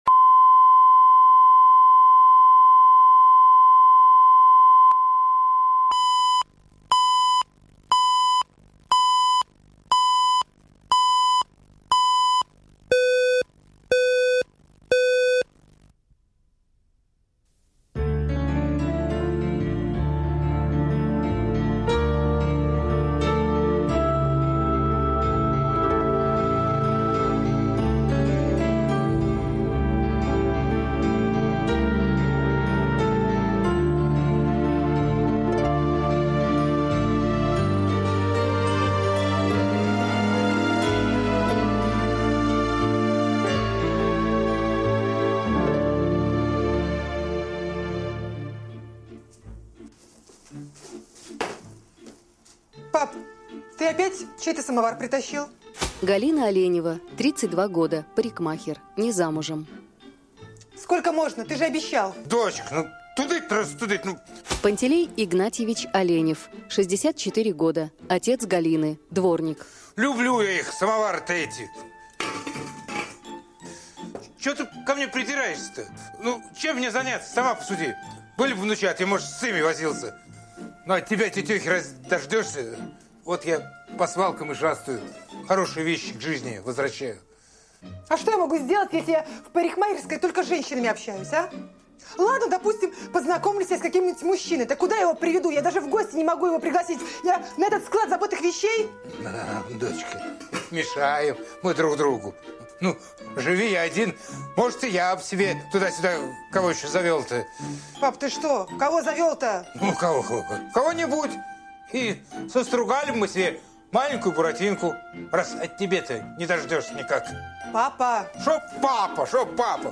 Аудиокнига Выйти замуж за поэта | Библиотека аудиокниг